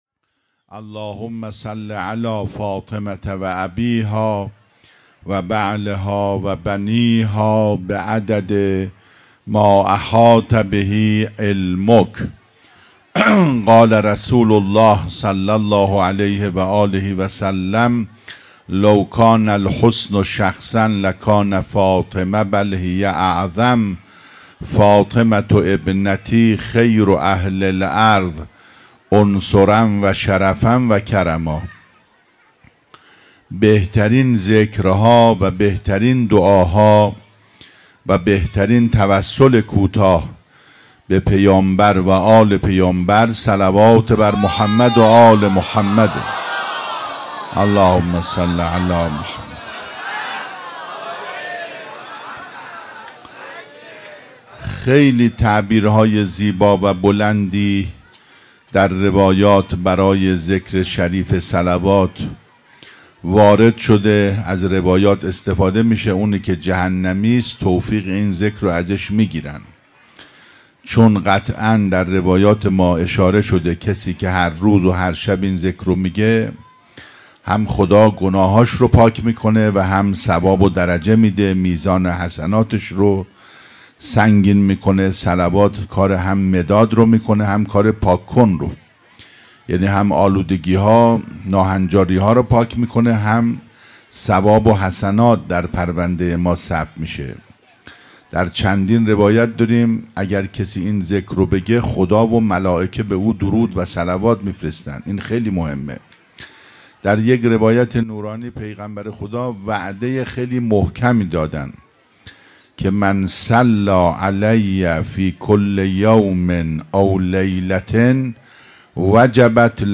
جشن کوثر ولایت15-مجمع دلسوختگان بقیع- سخنرانی شب دوم